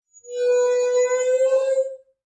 tr_dieseltruck_brake_04_hpx
Diesel truck air brakes release and screech. Vehicles, Truck Brake, Release